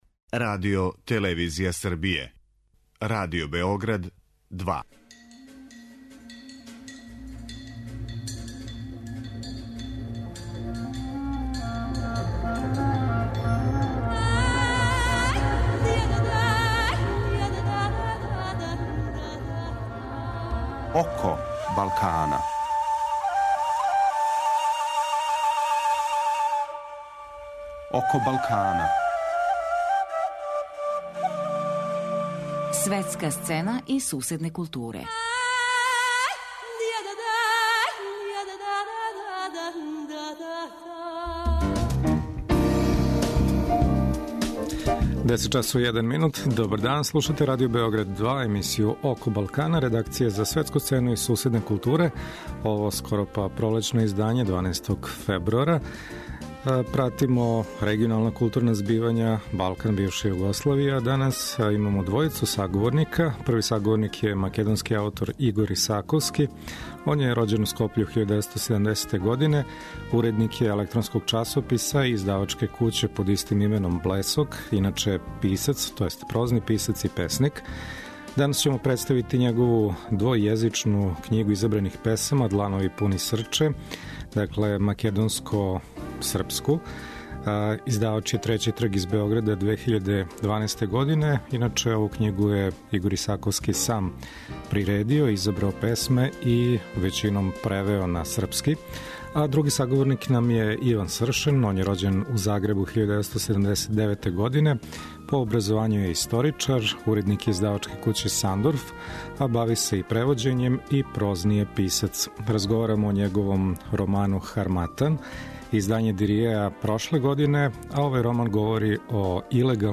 Eмисија је посвећена регионалним културама.